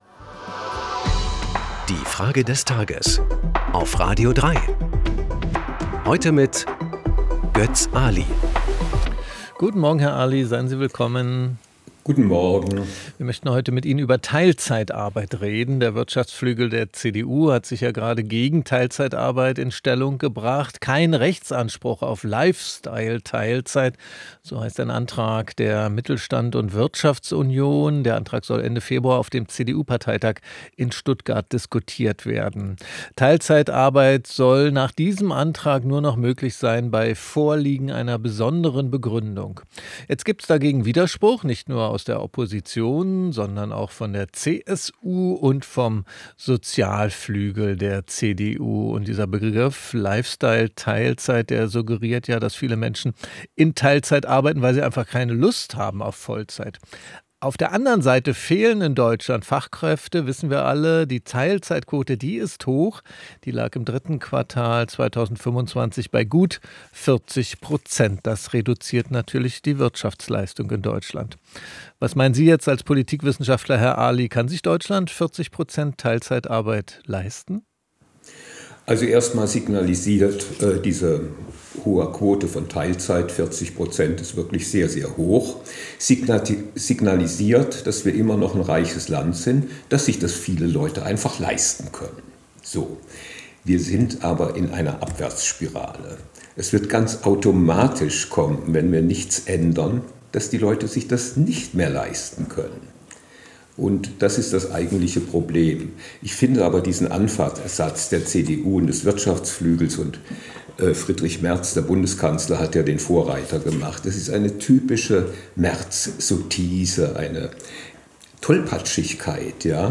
Politikwissenschaftler Götz Aly.